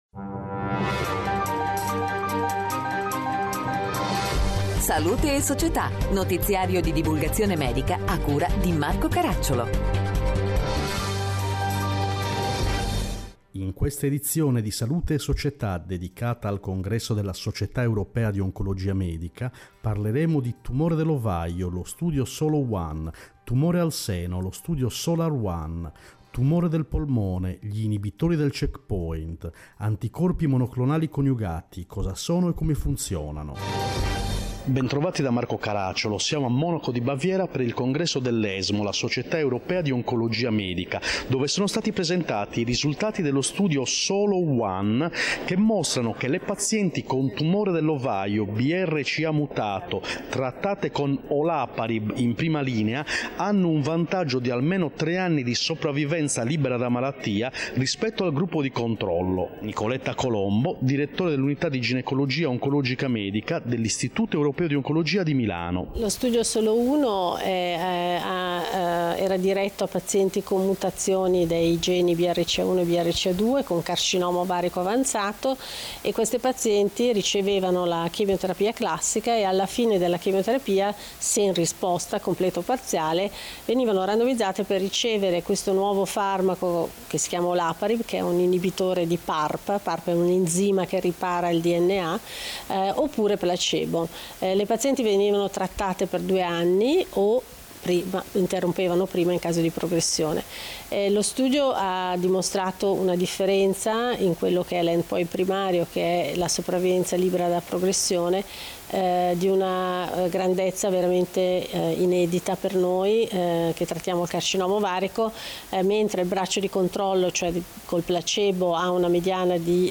In questa edizione dedicata al congresso della Società europea di oncologia medica, ESMO, a Monaco di Baviera: